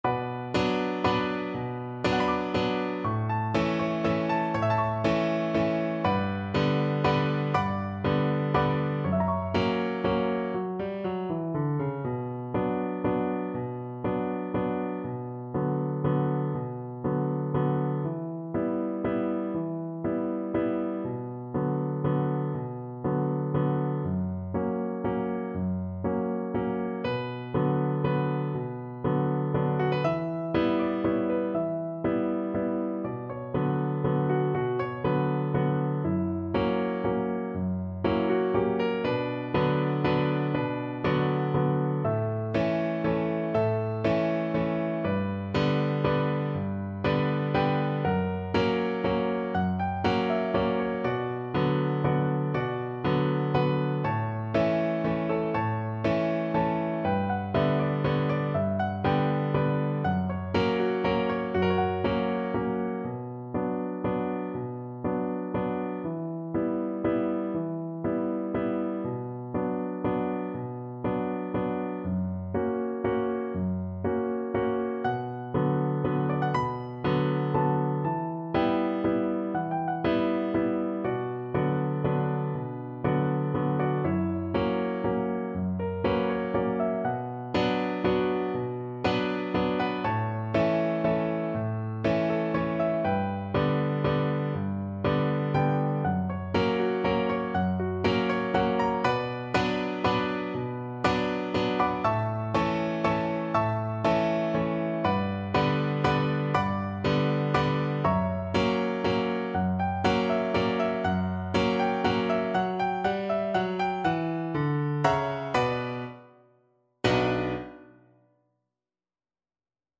Slow Waltz .=40
3/4 (View more 3/4 Music)